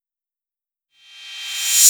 Track 08 - Reverse Cymbal OS 02.wav